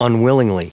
Prononciation du mot unwillingly en anglais (fichier audio)
Prononciation du mot : unwillingly
unwillingly.wav